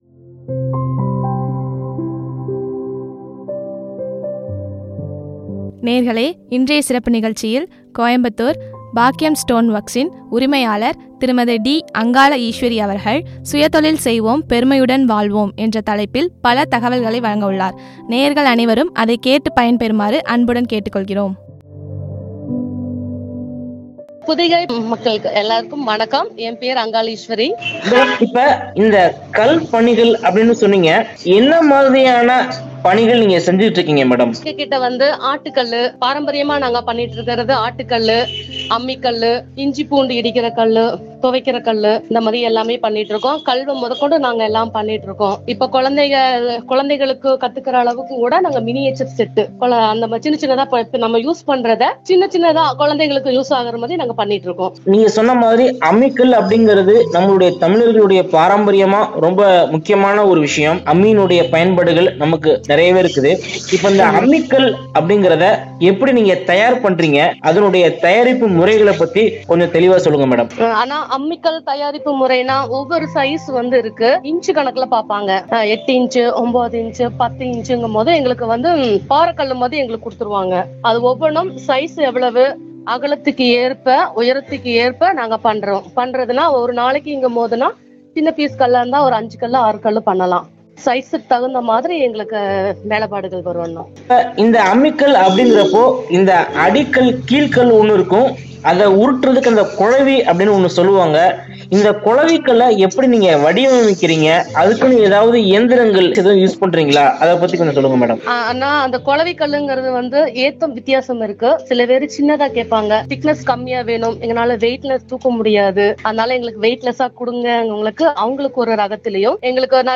நிகழ்ச்சி பற்றிய உரையாடல்.